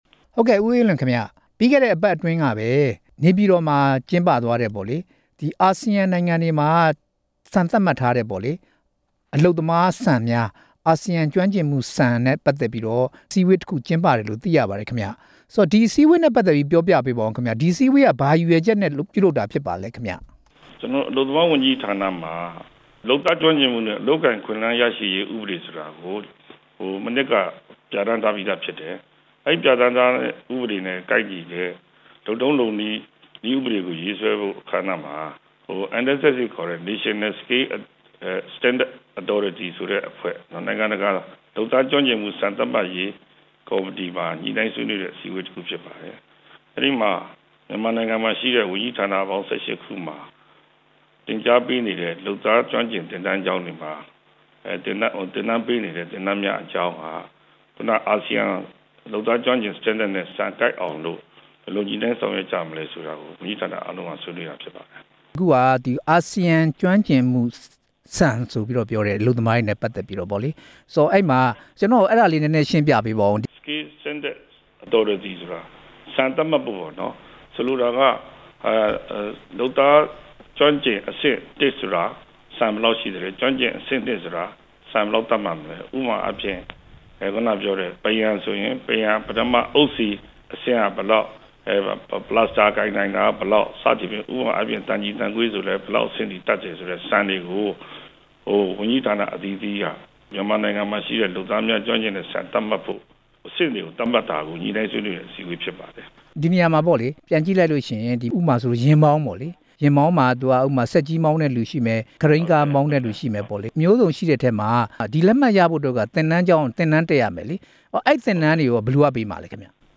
မြန်မာအလုပ်သမားတွေကို သင်တန်းပေးဖို့ကိစ္စ မေးမြန်းချက်